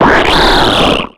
Cri d'Amphinobi dans Pokémon X et Y.